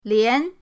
lian2.mp3